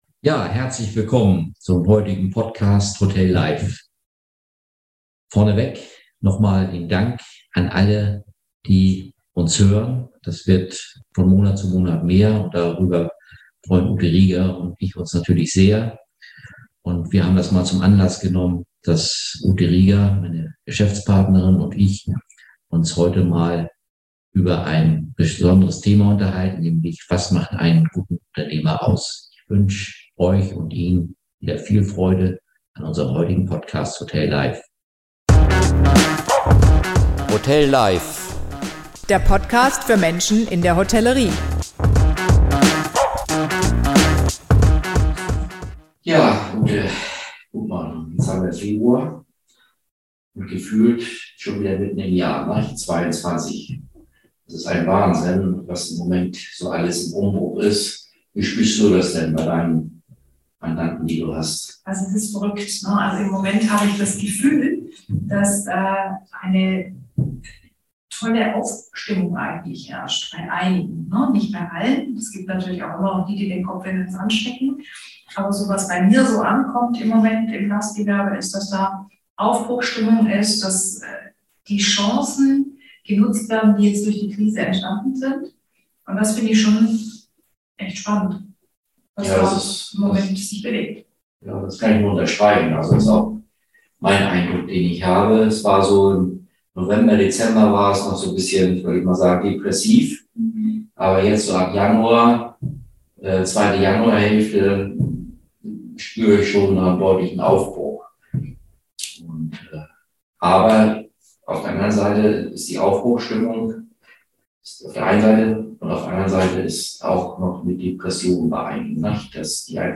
Dieses Mal ohne Gast.